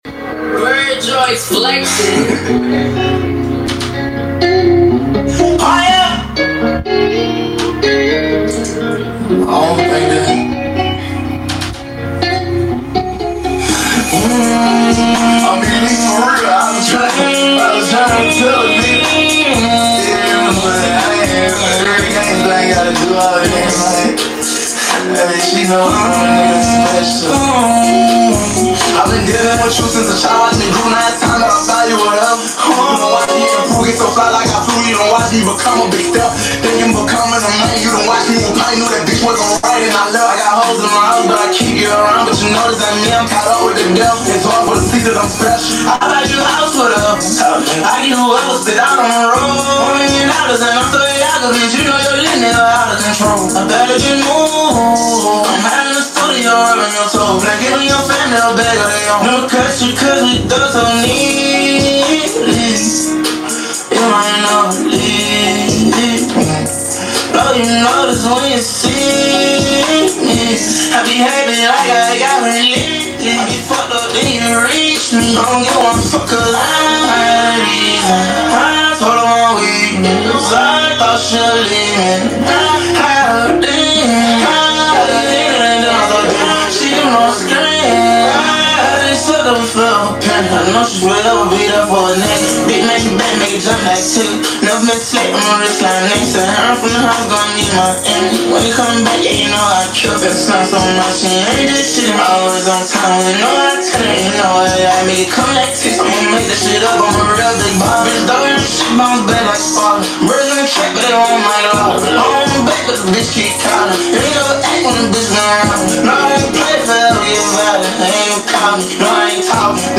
Full song not in CDQ THO